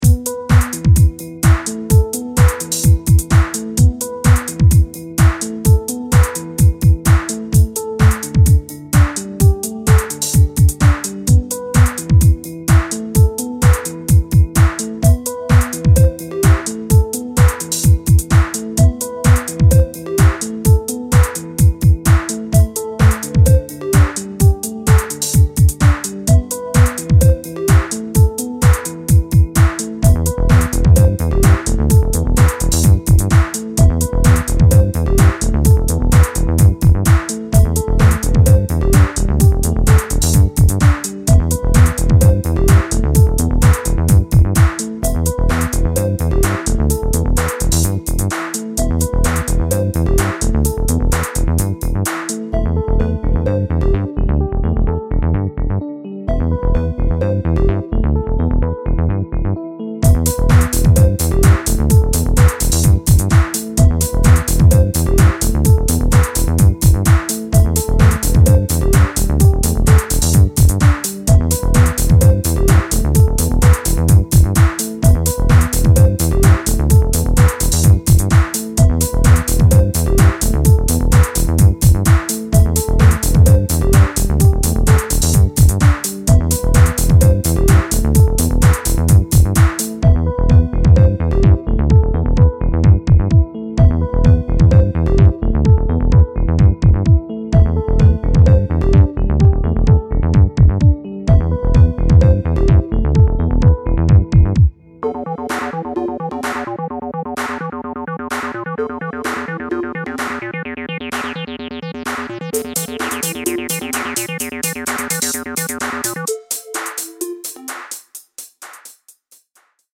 Progressive , Tech House , Techno